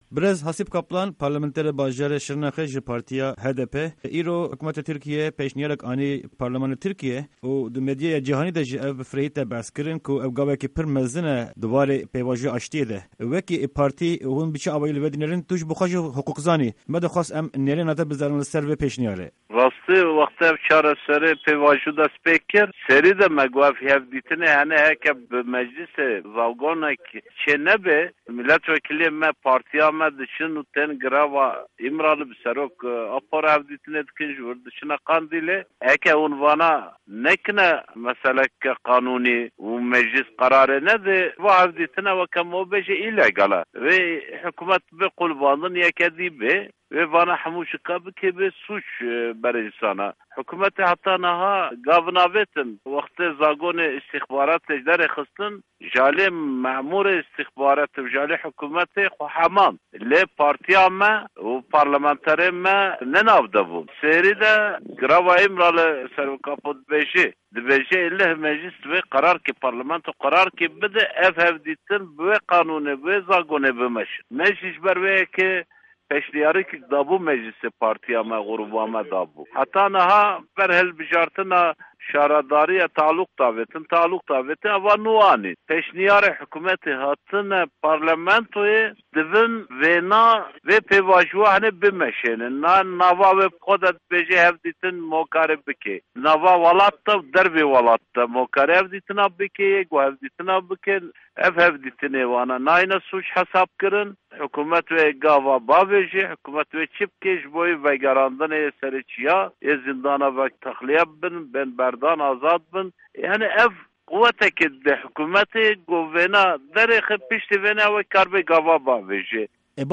Di hevpeyvîna Dengê Amerîka de, parlamenterê Partîya Demokratîk ya Gelan (HDP) Hasîp Kaplan li ser vê pêşnîyarê nêrînên xwe ligel guhdarên me parve dike.